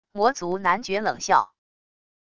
魔族男爵冷笑wav音频